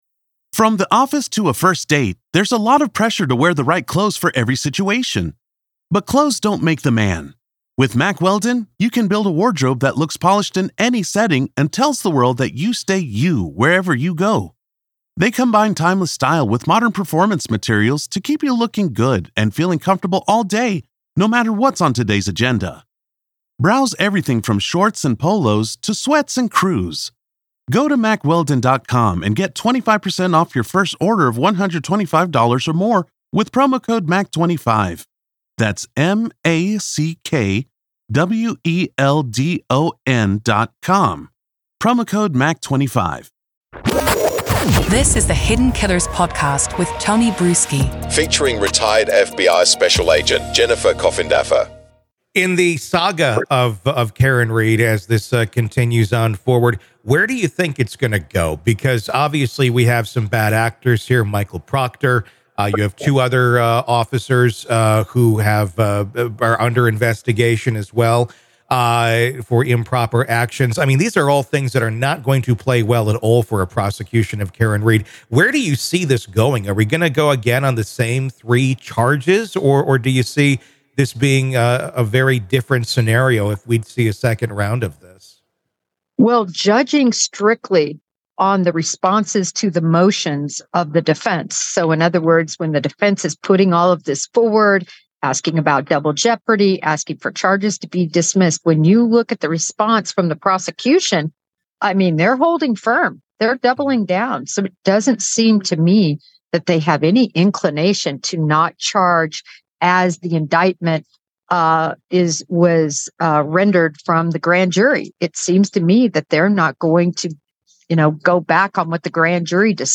The conversation explores the potential strategies for both the defense and prosecution in a retrial, including the defense's possible shift in approach.